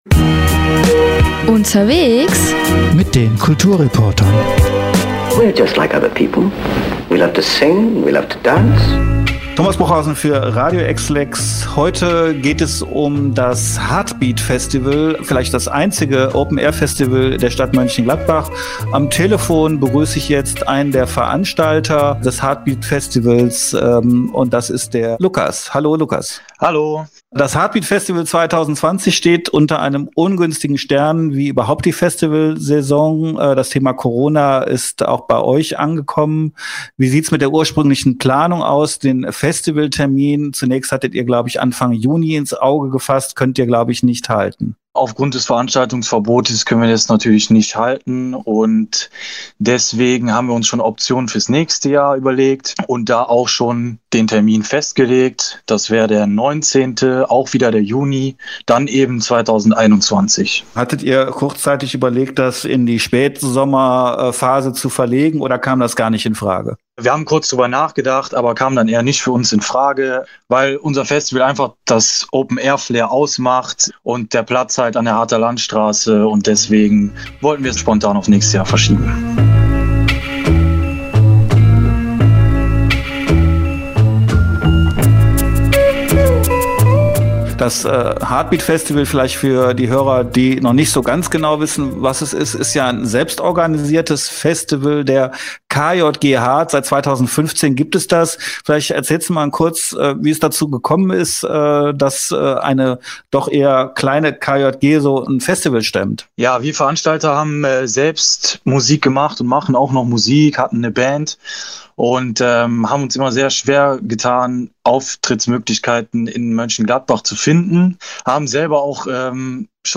Interview-Hardtbeat-Komplett-TB-AR_WEB.mp3